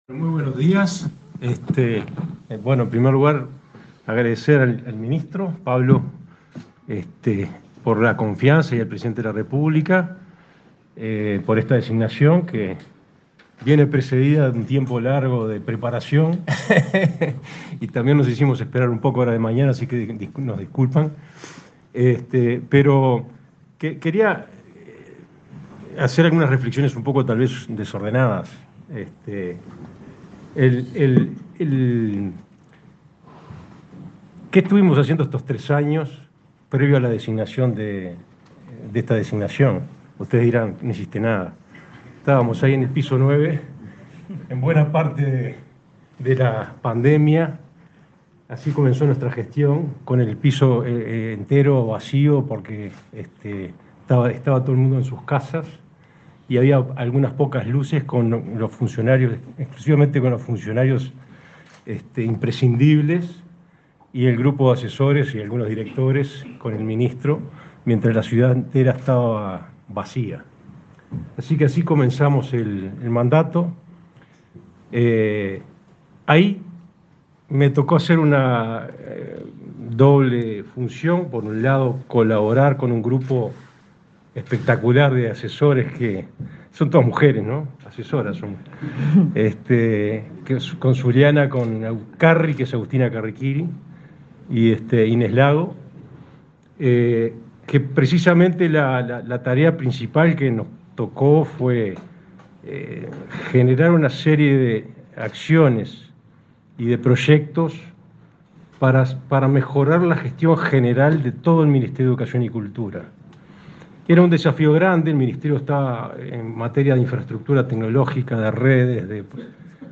Palabras de autoridades del Ministerio de Educación y Cultura
Este miércoles 19, el ministro de Educación y Cultura, Pablo da Silveira, asistió, en la sede de la cartera, a la toma de posesión del cargo del